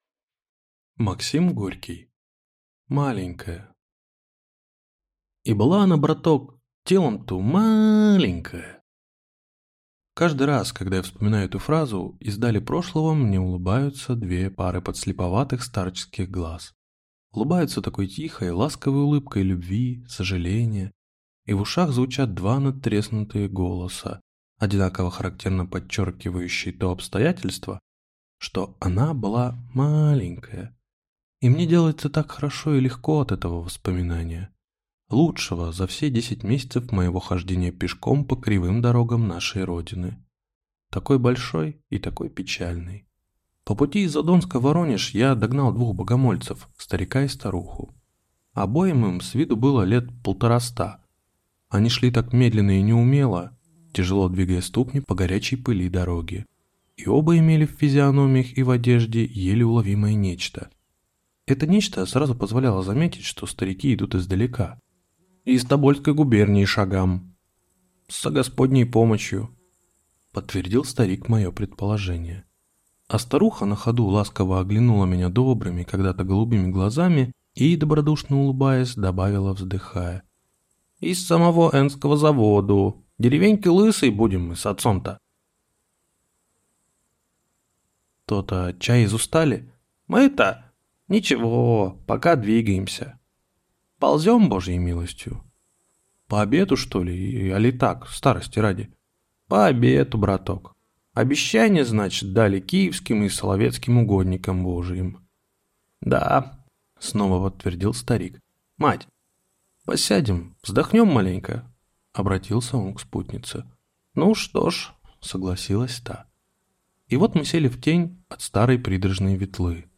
Аудиокнига Ма-аленькая!